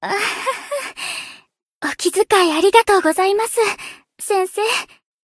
贡献 ） 分类:蔚蓝档案语音 协议:Copyright 您不可以覆盖此文件。